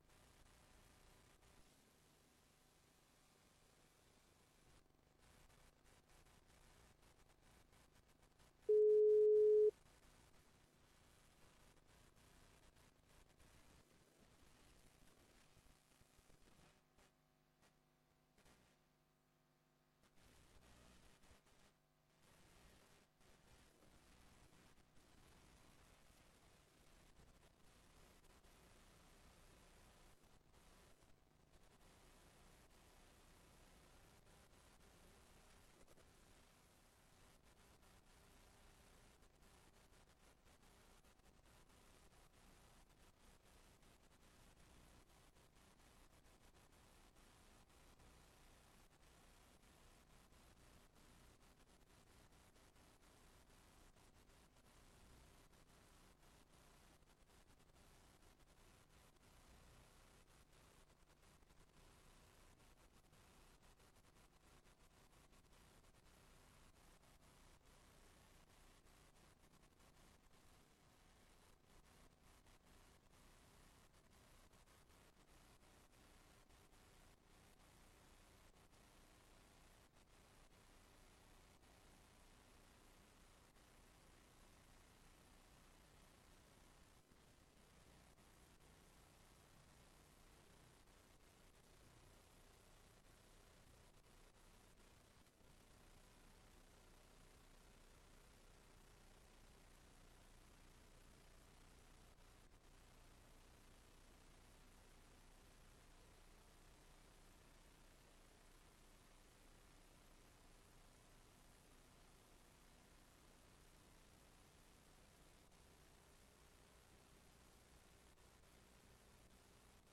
Raadsbijeenkomst 24 juni 2025 20:15:00, Gemeente Tynaarlo